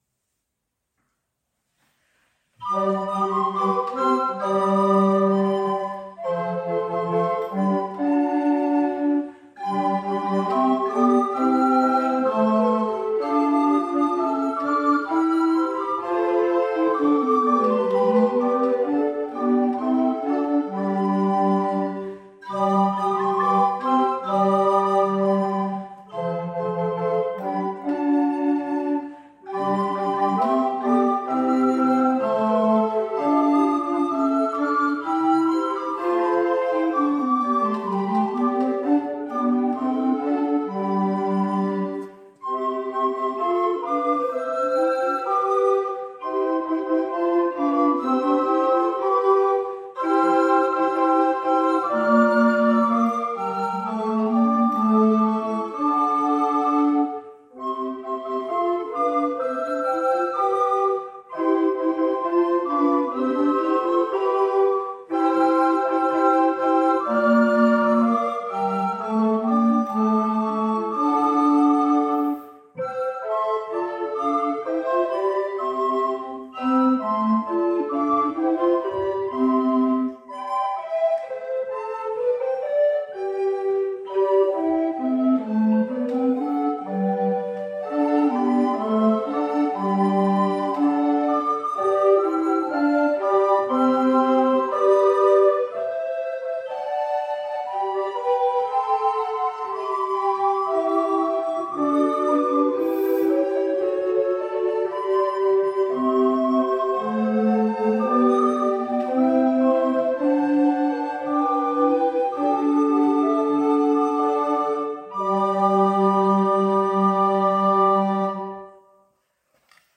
Hier können Sie gern einige Klangeindrücke unseres Ensembles gewinnen: